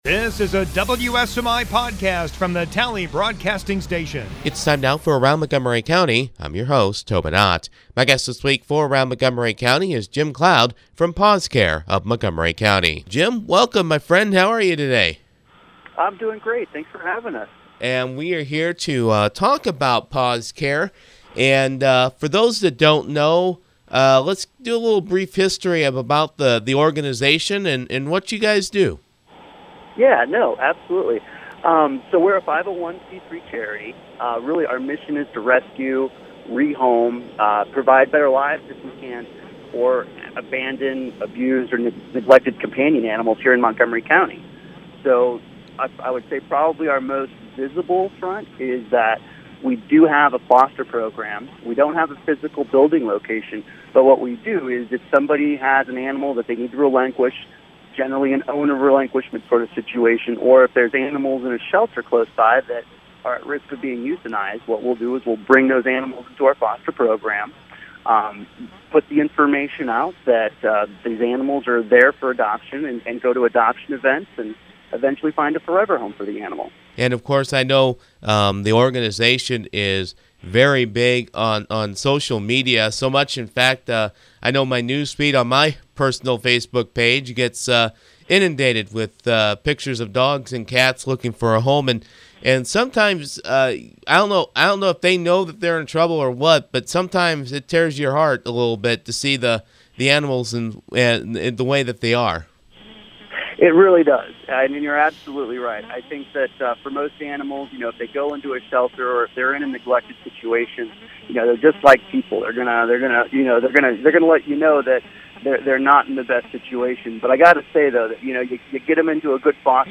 Guest: